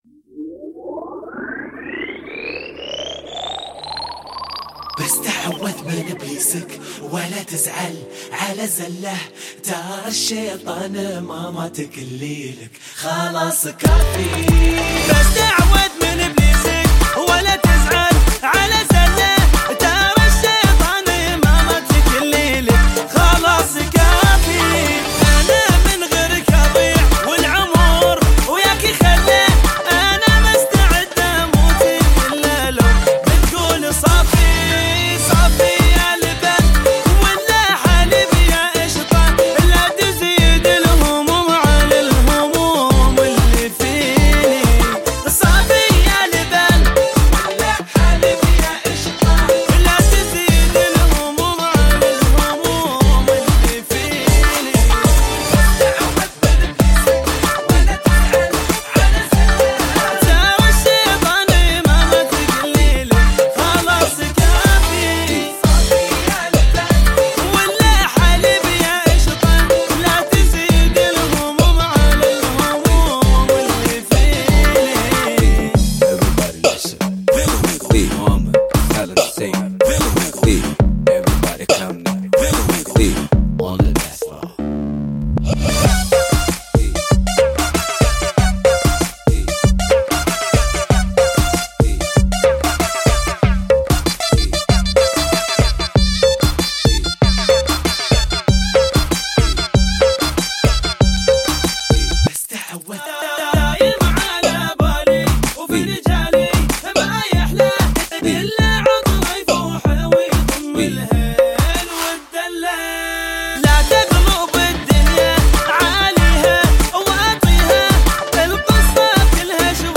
ژانر: شوتی